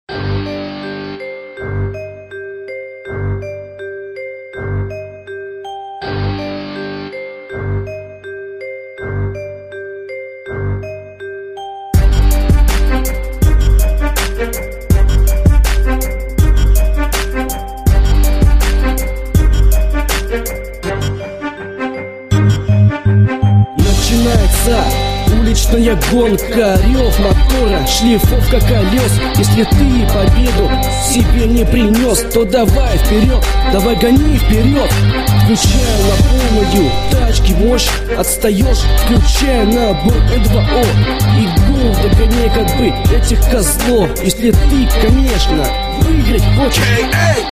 Хы..это дэмо-версия,доделать руки не доходят